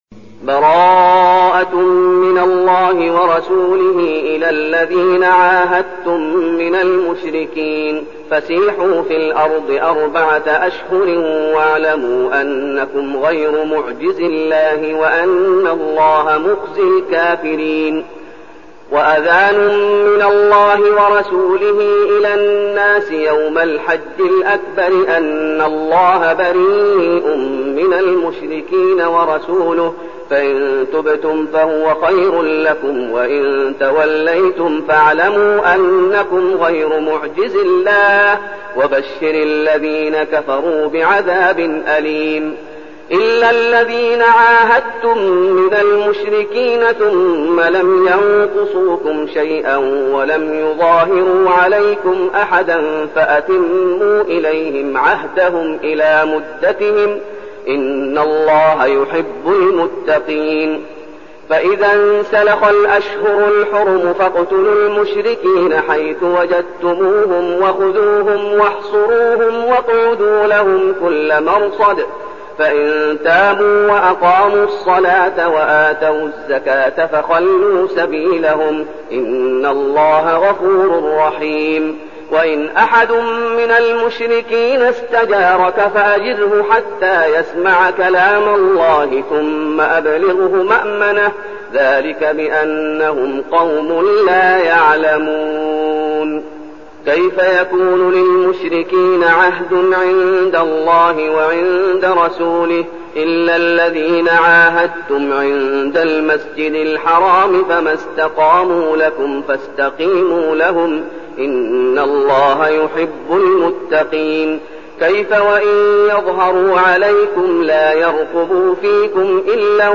المكان: المسجد النبوي الشيخ: فضيلة الشيخ محمد أيوب فضيلة الشيخ محمد أيوب التوبة